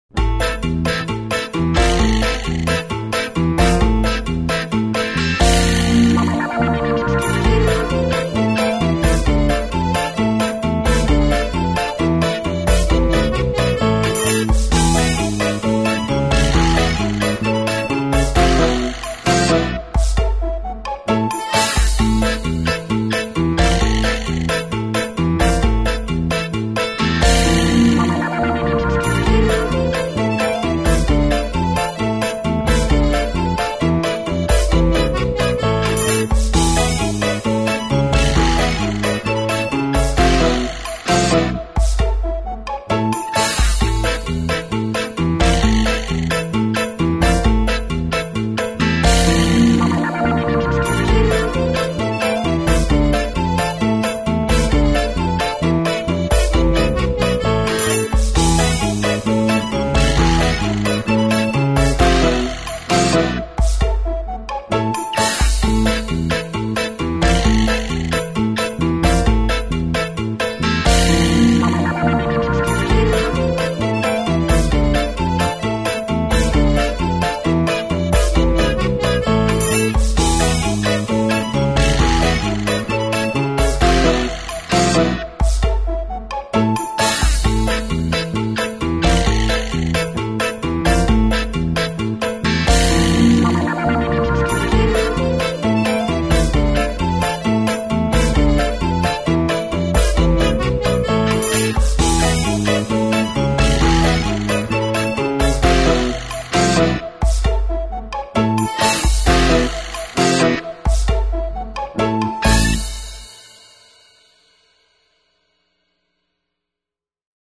instrumentale versie van het krakmomentlied.
krakmoment2003_muzikaal.mp3